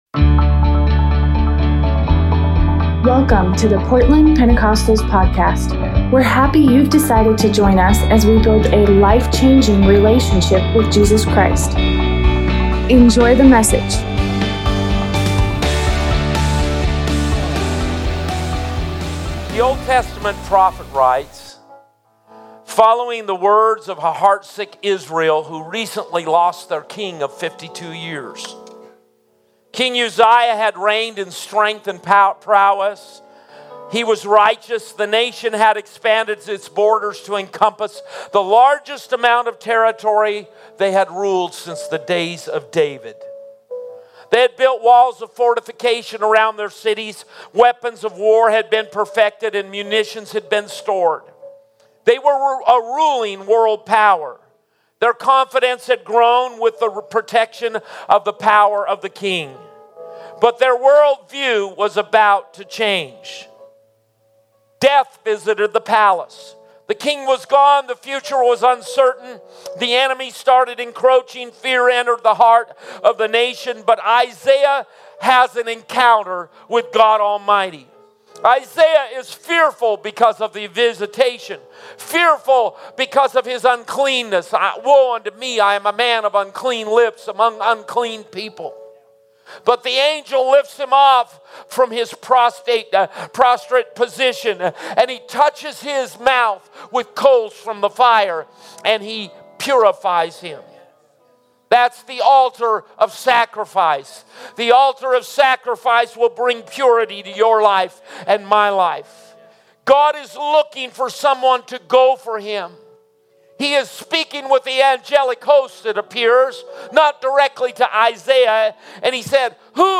Service Type: Edification and Encouragement